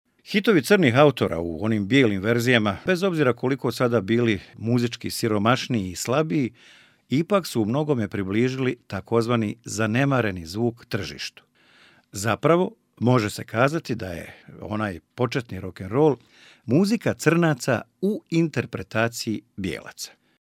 Croatian male voice over talent